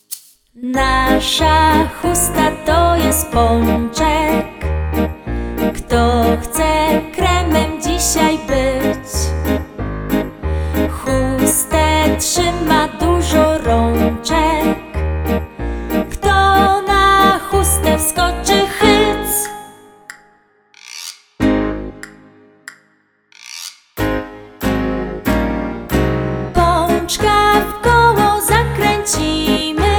utwór w wersji wokalnej
Zabawy muzyczne